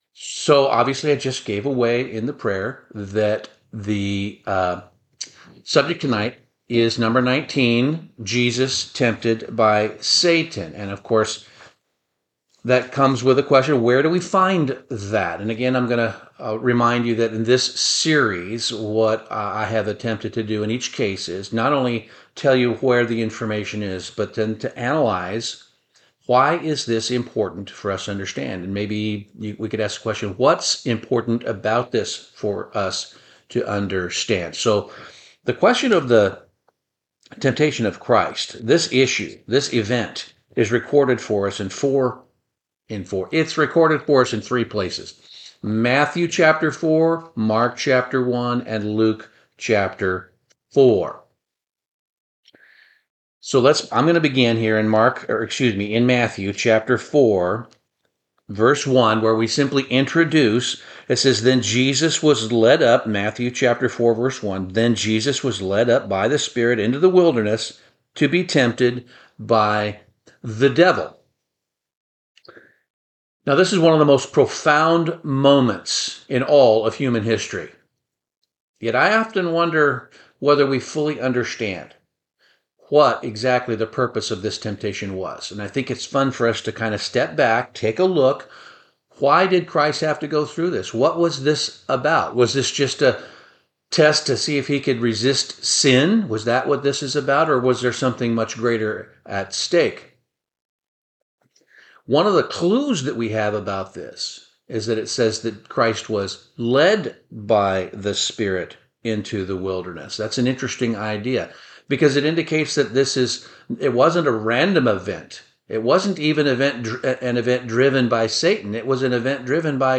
Bible Study - The Temptation of Christ